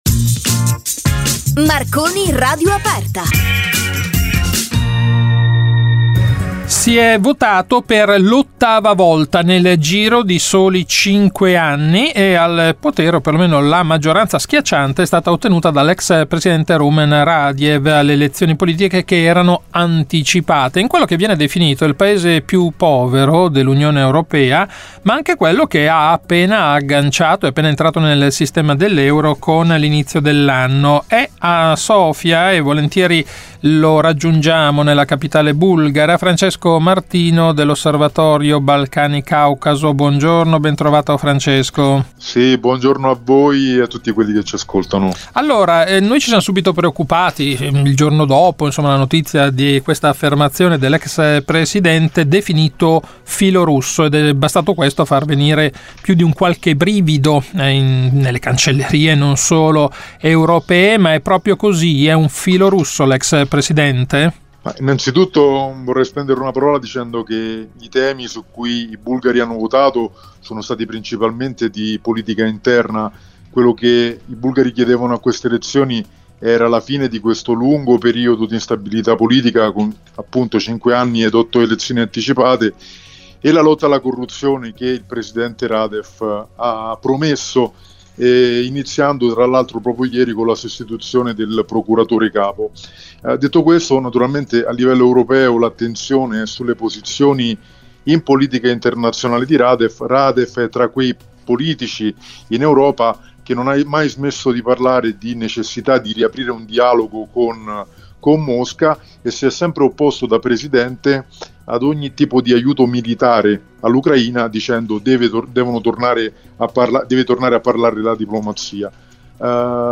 in diretta da Sofia